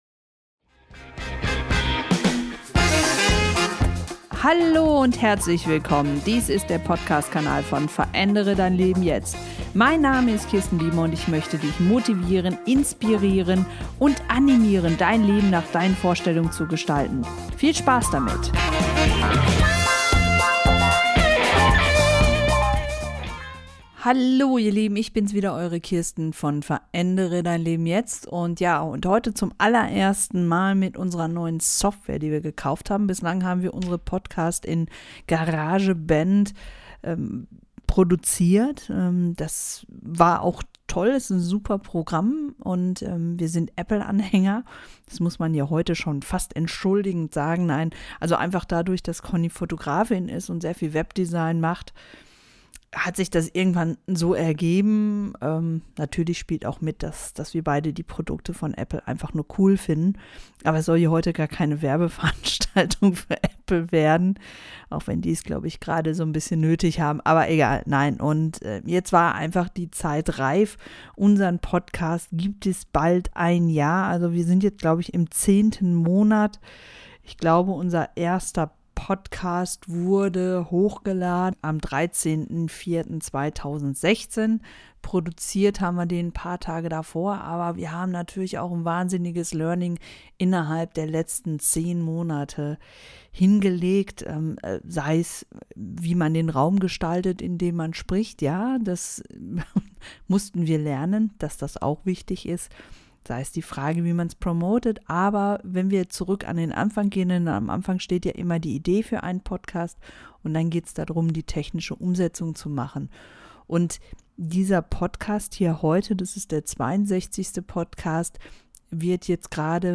Hört einfach mal rein, bei einem heute sehr entspannten und ganz anderen Podcast, als sonst.